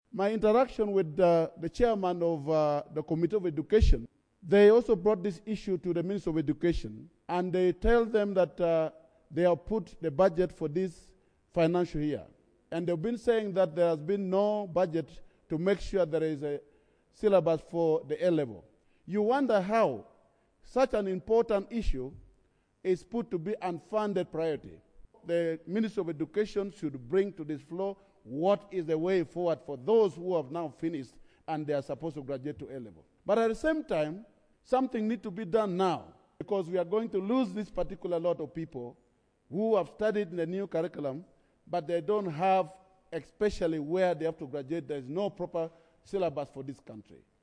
Akol raised the concern as a matter of national importance during the plenary sitting on Wednesday, 22 January 2022.